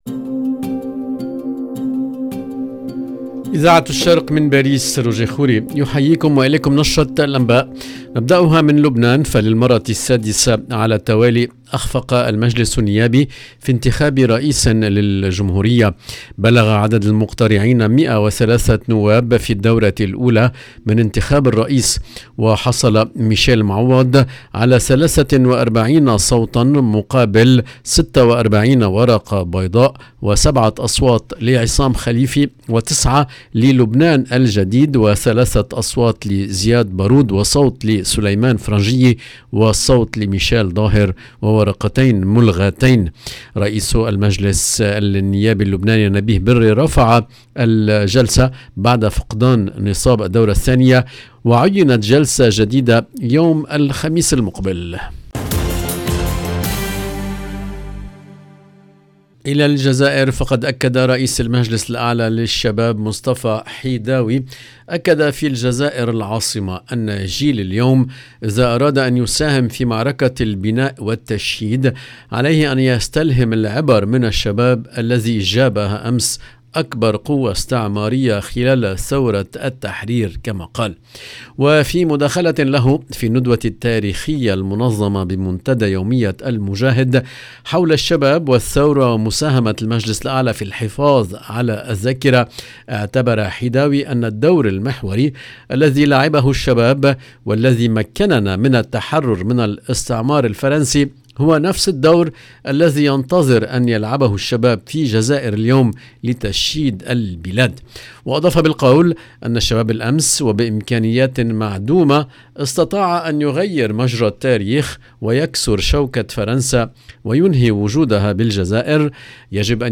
EDITION DU JOURNAL DU SOIR EN LANGUE ARABE DU 17/11/2022